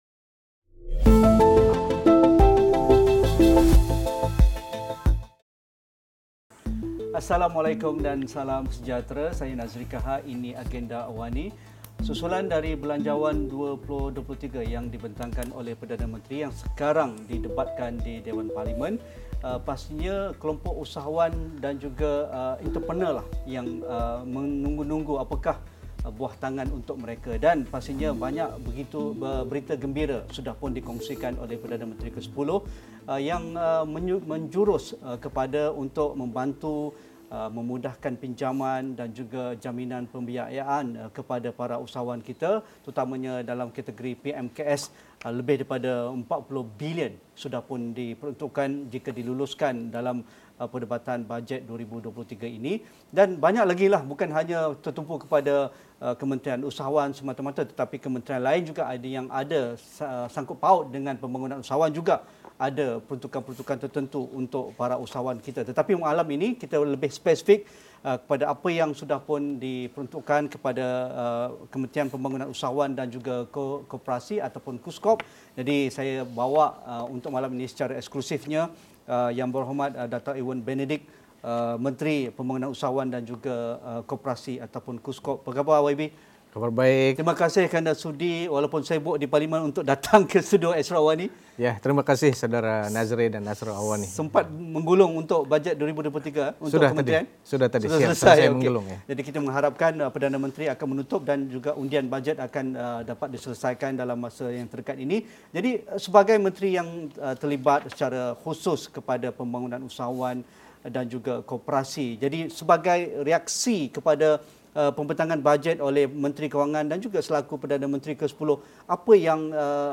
Temu bual bersama Menteri KUSKOP, YB Datuk Ewon Benedick dalam Agenda Awani 9 malam ini.